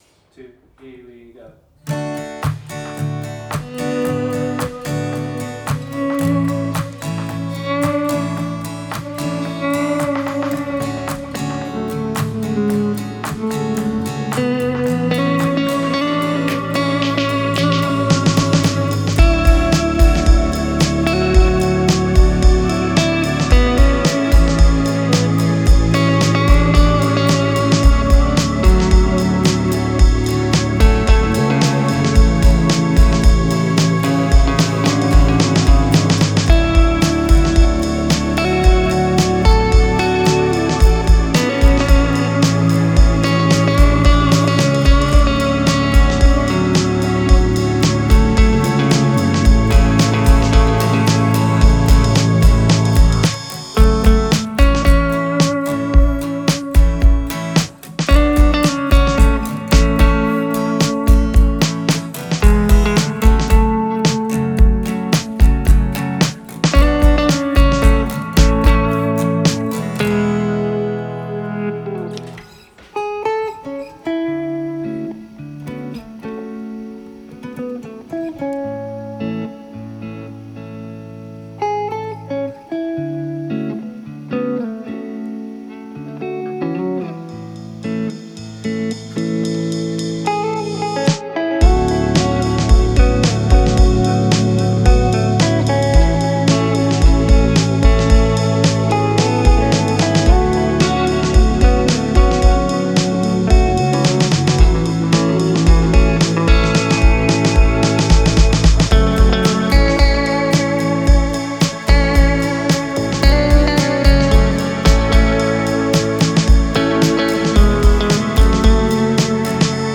TR8S drum machine
the AZ-based duo